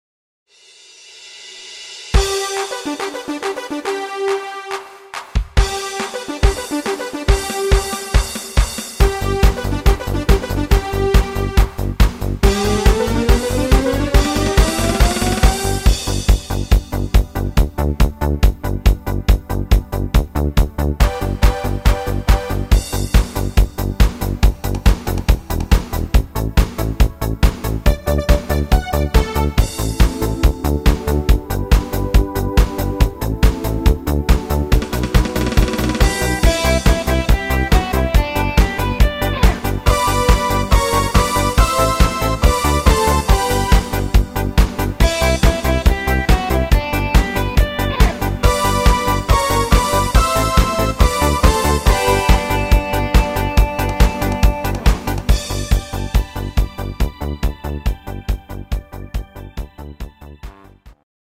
Partymix